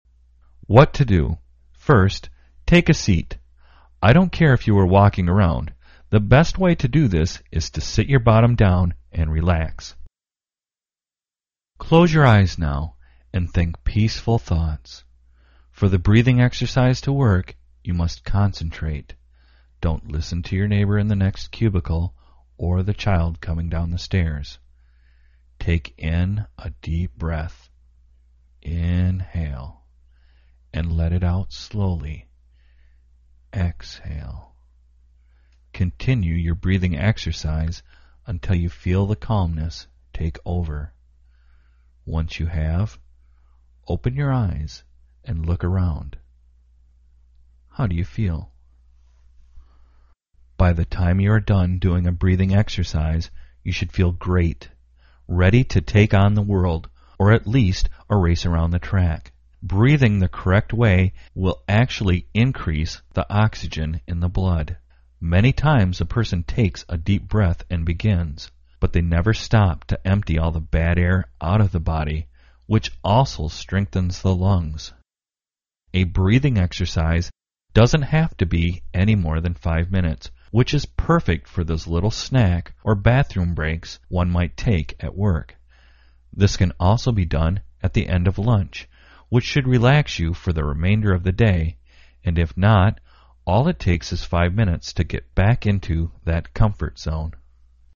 Exercise Fitness and You! Audio Book